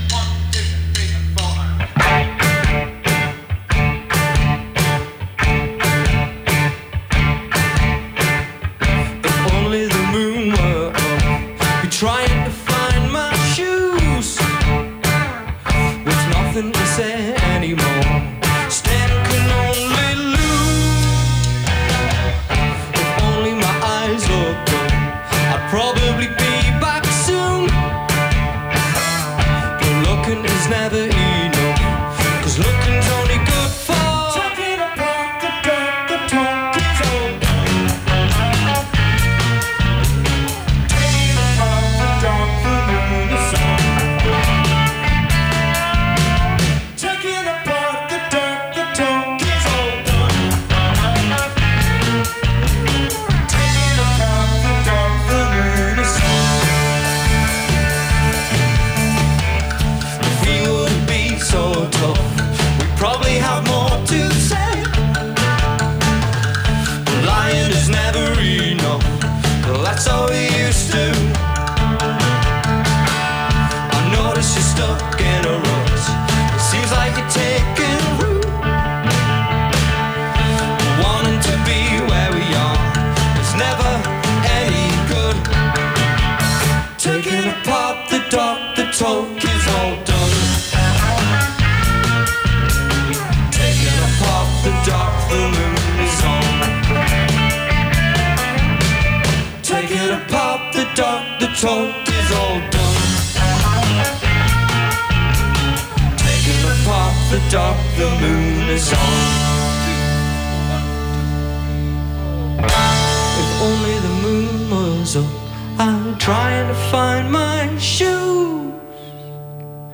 enregistrée le 22/02/2010  au Studio 105